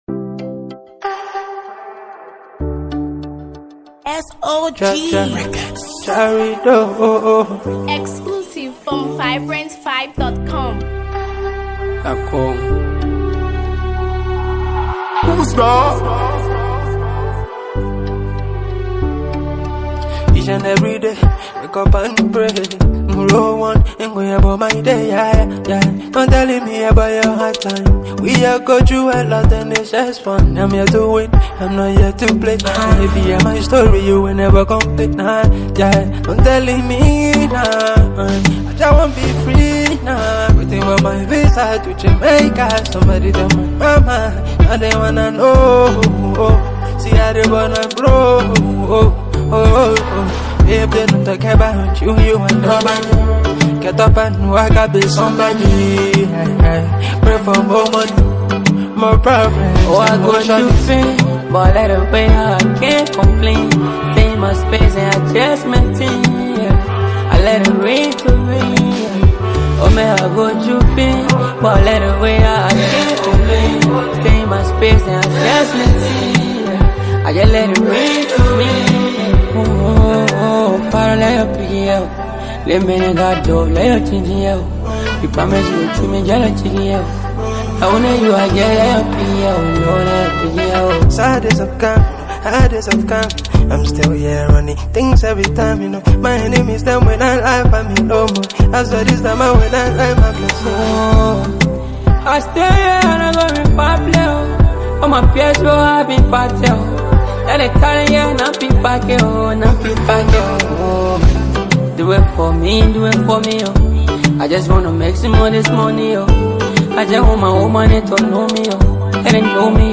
laid‑back yet infectious new single
creating a seamless blend of R&B and Afro‑pop.